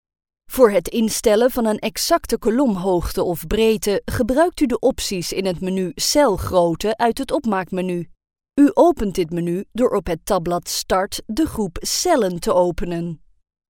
Recording in our own professional studio, we deliver quality clean and crisp tracks.
Sprechprobe: eLearning (Muttersprache):
Smooth, professional, playful, natural, Dutch.